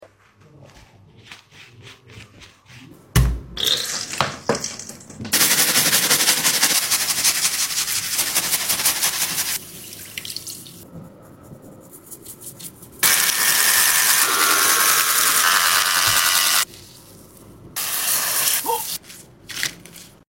CLEANING SQUISHY ASMR🥒🥒Cucumber Man#asmr